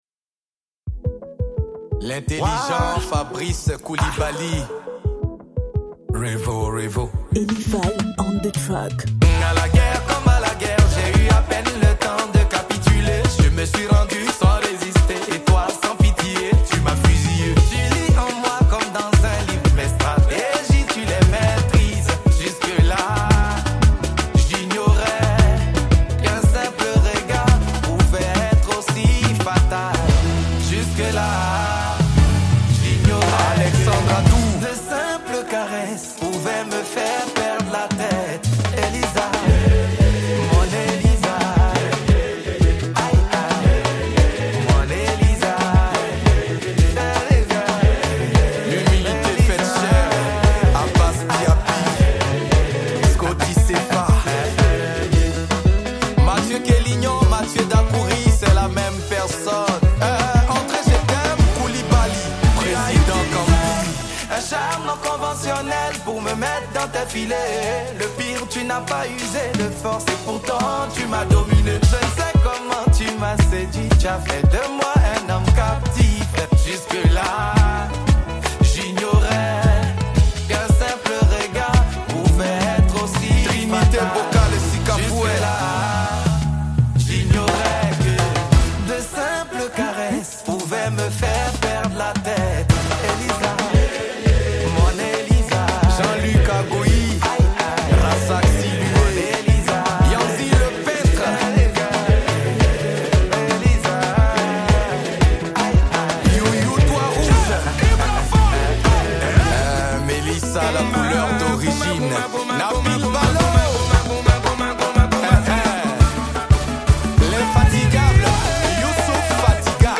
| Zouglou